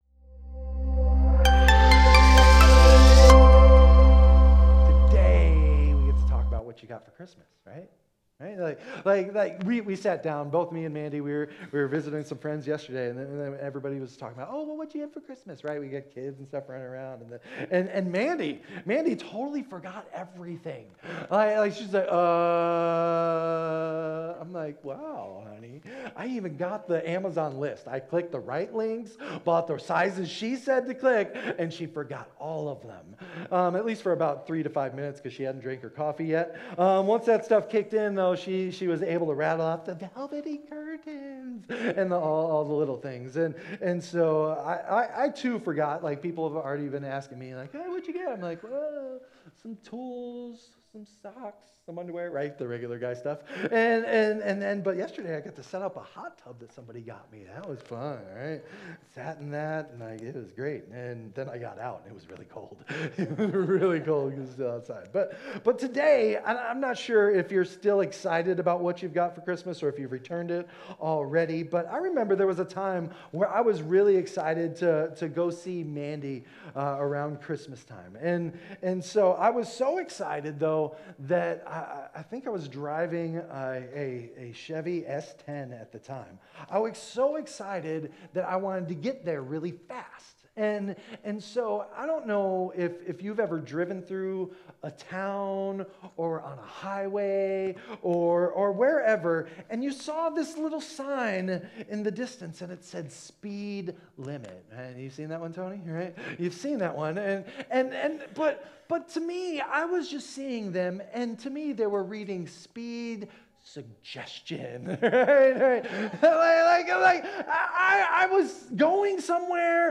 In this sermon, we explore God’s original design for humanity and the consequences of veering off course.